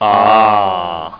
aah.mp3